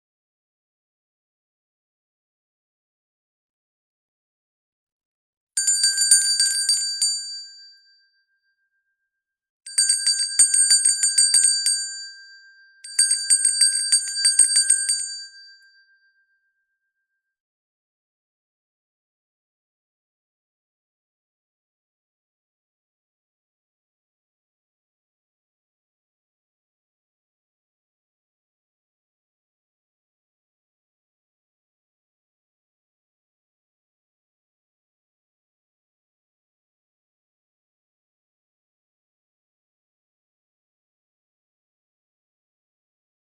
Vánoční zvoneček ke stažení
Mohutnější zvoneček
MOHUTNEJSI-ZVONECEK.mp3